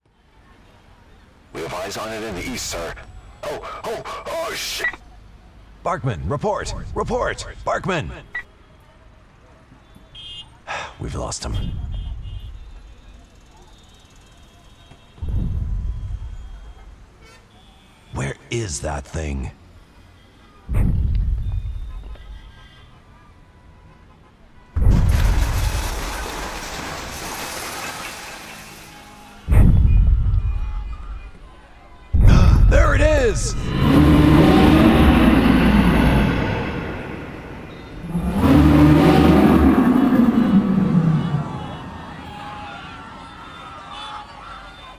Sound design demos
Kaiju attack